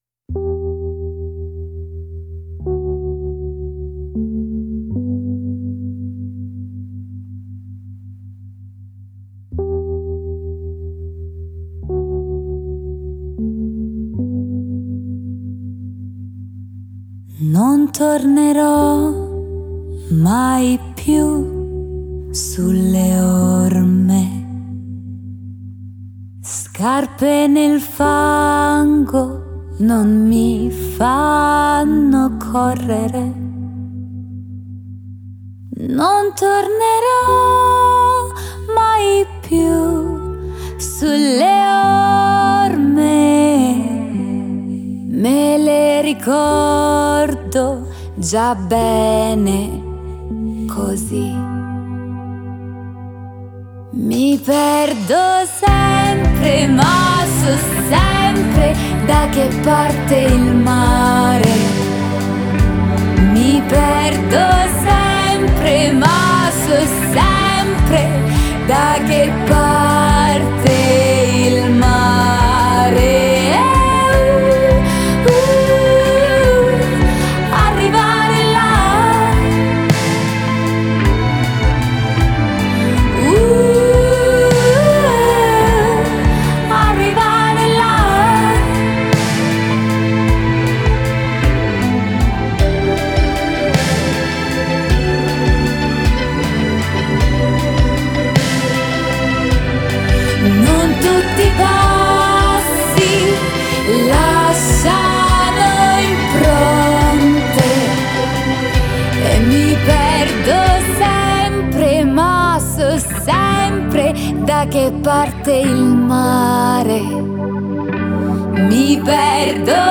Genre: Pop Rock, Indie